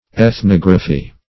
Ethnography \Eth*nog"ra*phy\, n. [Gr.